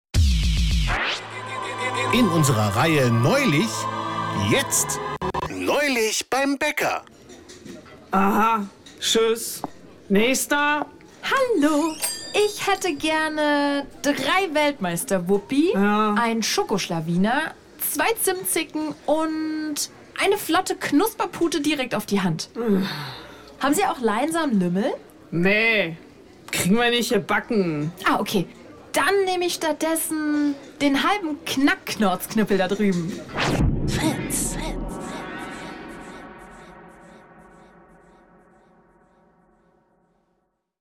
Das ist Comedy als Microdosing.